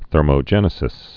(thûrmō-jĕnĭ-sĭs, -mə-)